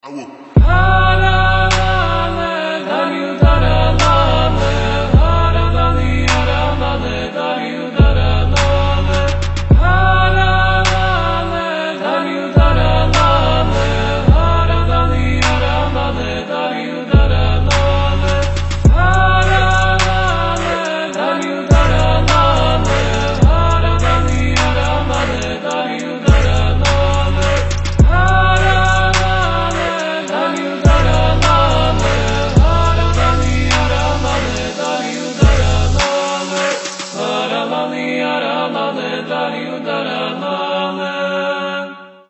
спокойные
Грузинские
нежные
Trap & Bass